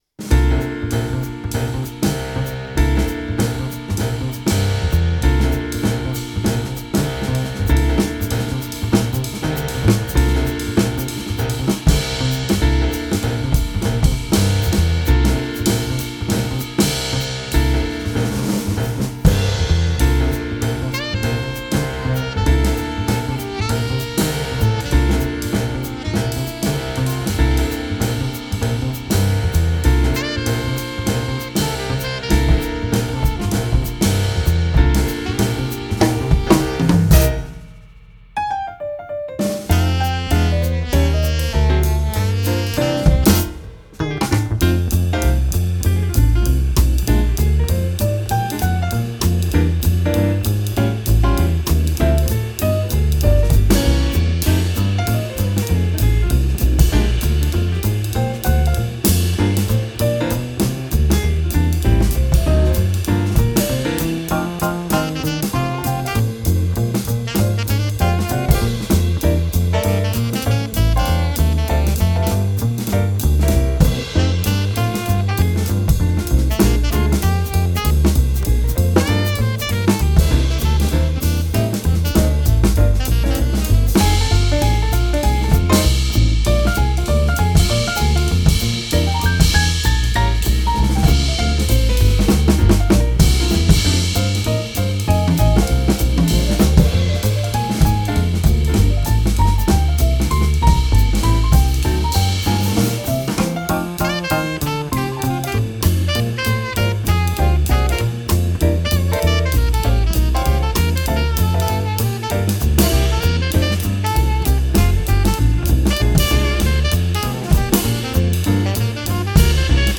1. Challenge 2026 - Jazz (Ergebnisse)
4/4 auf 195 bpm. 3 Takte Stille -> 2 Takte Einzähler.
Meine Mastering-Kette halte ich dabei subtil mit etwas Kompression, sachtem EQing und einer Tonband-Simulation.